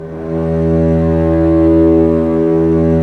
Index of /90_sSampleCDs/Roland LCDP13 String Sections/STR_Vcs FX/STR_Vcs Sordino